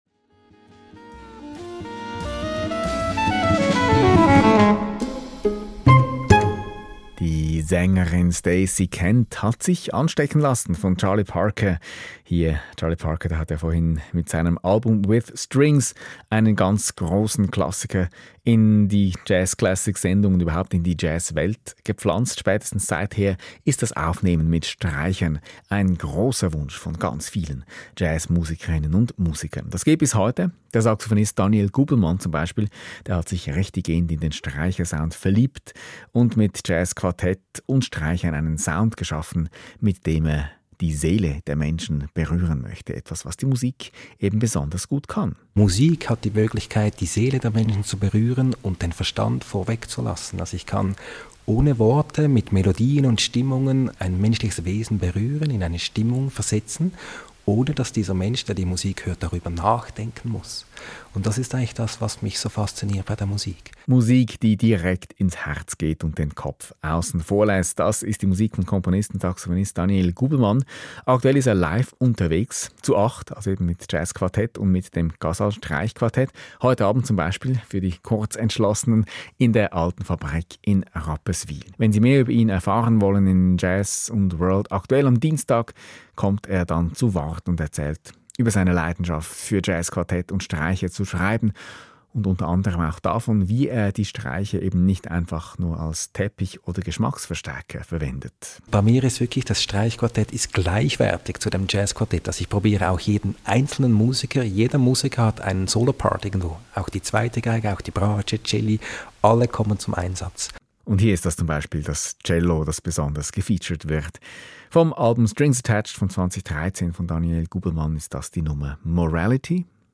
Interview – Portrait, FIVE ON FIRE feat. Casal Quartett / Jazz Classics – SRF 2 Kultur / (Schweiz, 2024)